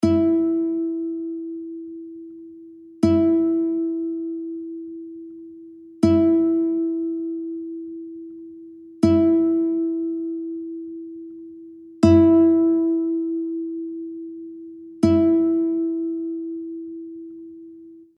2-га струна мі, E (mp3) – у давньому строї відсутня:
Bandurka_C-strij_2_E4.mp3